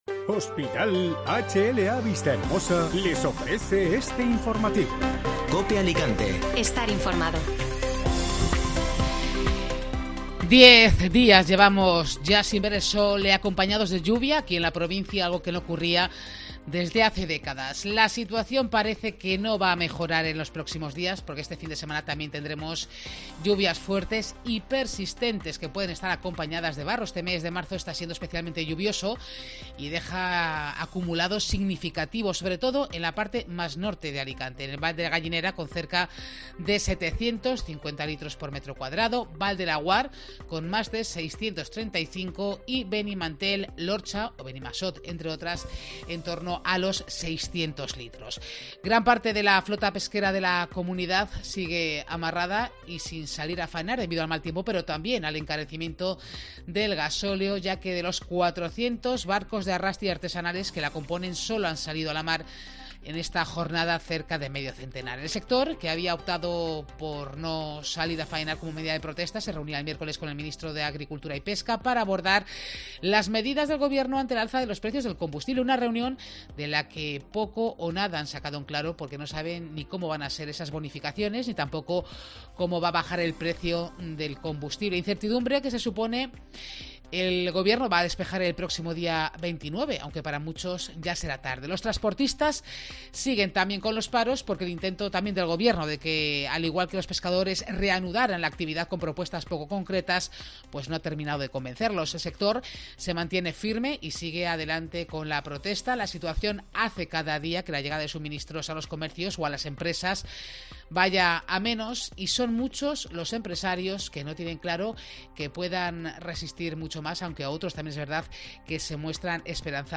Informativo Mediodía COPE Alicante (Viernes 25 de marzo)